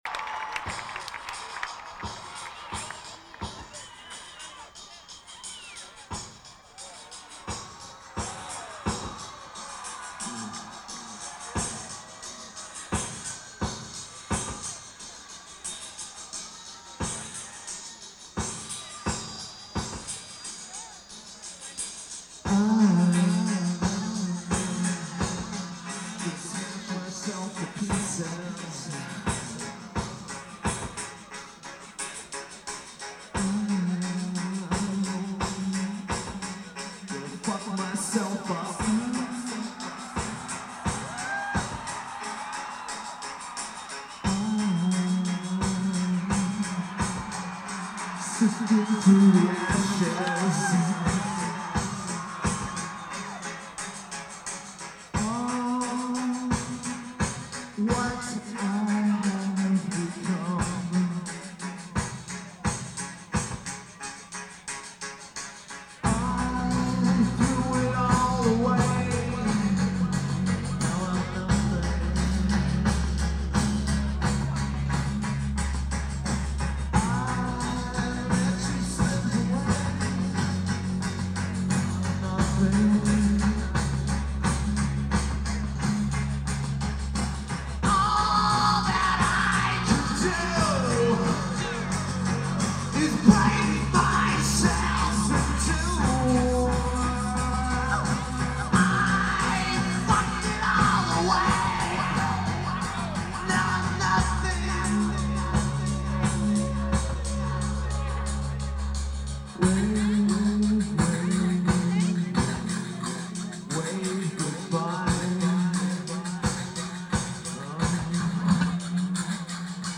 Harriet Island
Lineage: Audio - AUD (Sony WM-D6 + Unknown Mic)
This is a great recording.